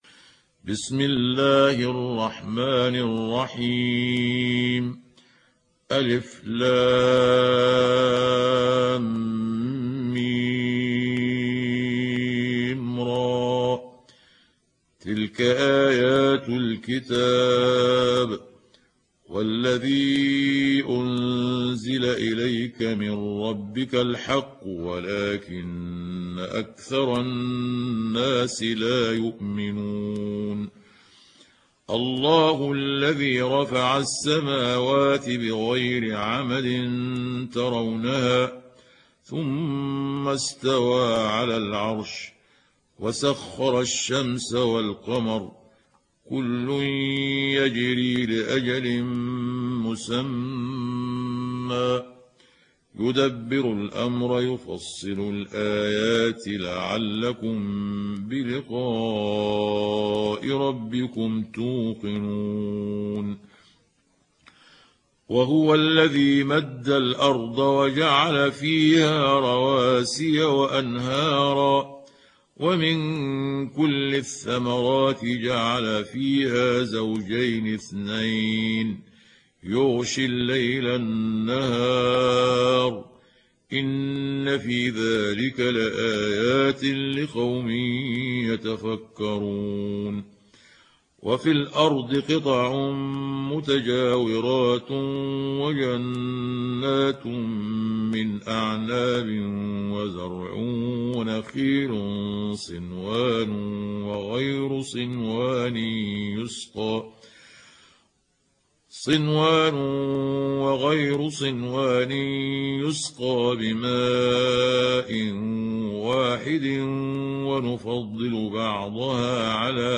تحميل سورة الرعد mp3 بصوت محمود عبد الحكم برواية حفص عن عاصم, تحميل استماع القرآن الكريم على الجوال mp3 كاملا بروابط مباشرة وسريعة